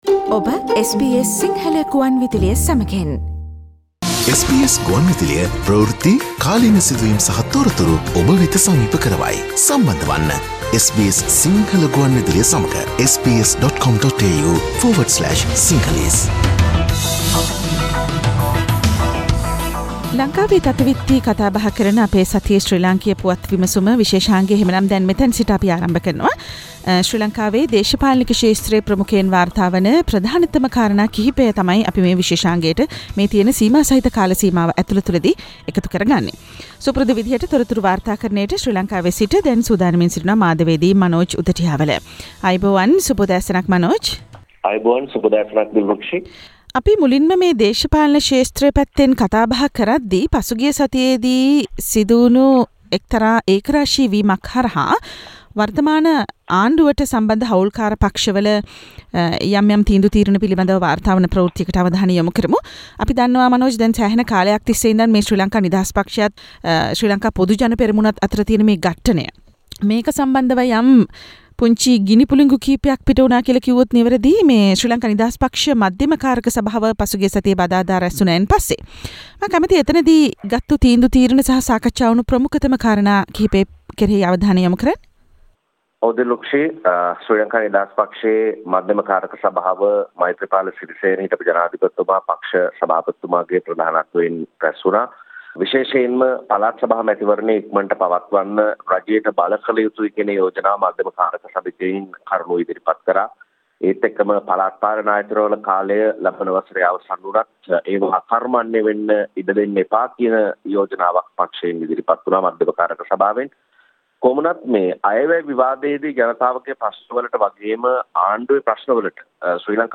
ආණ්ඩුවෙන් එලියට බහින්නේ තවත් 60 කුත් අරන්, සරල බහුතරය වත් නැතිවේවී - SLFP වෙතින් රතු එළියක්: ශ්‍රී ලංකා පුවත් විමසුම